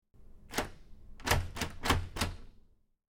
Rattle, Door
Door Knob Jiggle With Room A / C, X2